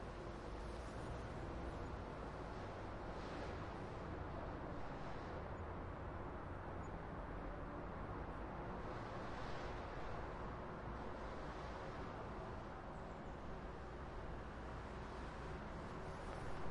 描述：街道的氛围声音。用Zoom H4n + Rode麦克风改装。
Tag: 环境 交通 街道 汽车 城市 噪音